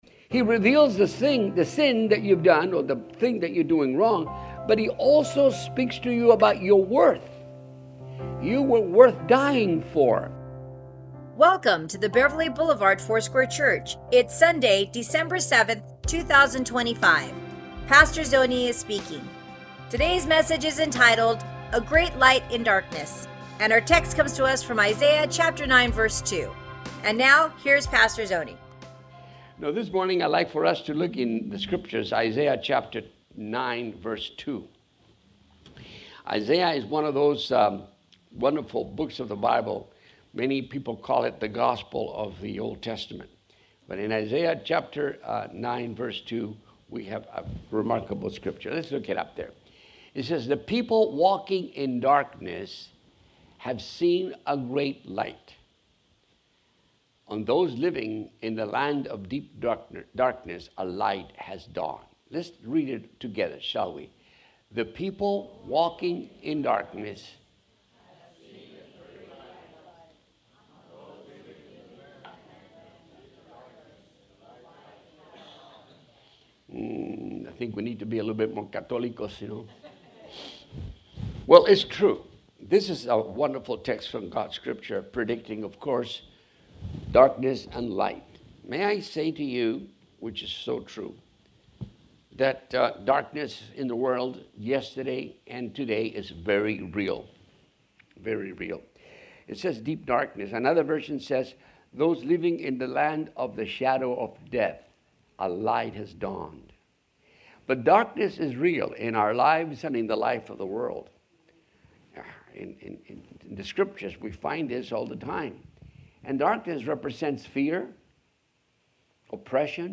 Sermons | Beverly Boulevard Foursquare Church